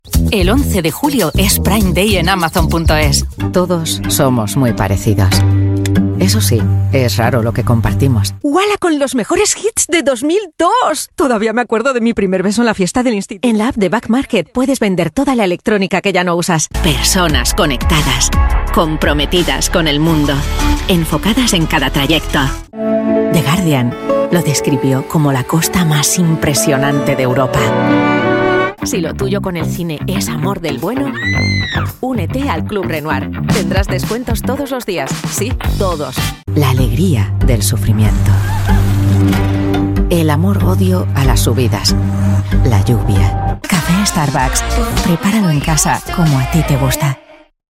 Naturelle, Polyvalente, Profonde, Accessible, Chaude
Commercial